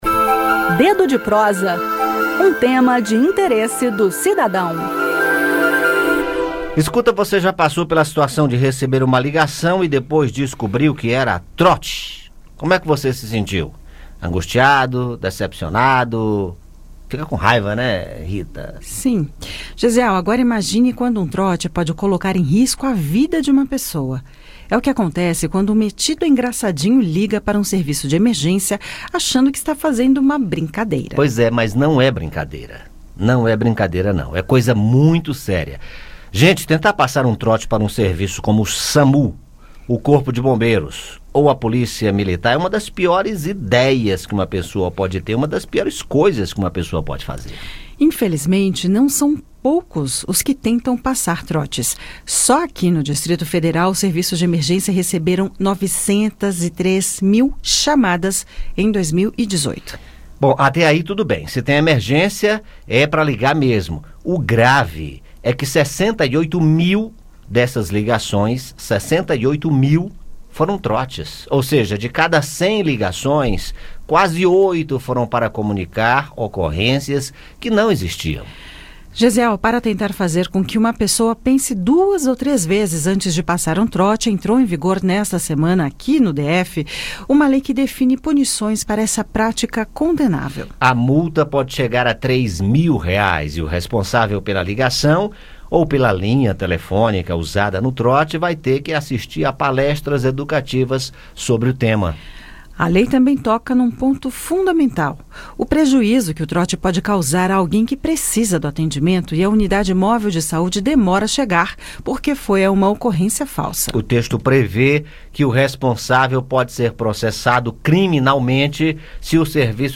E o combate aos trotes é o assunto do "Dedo de Prosa" desta segunda-feira (16). Ouça o áudio com o bate-papo.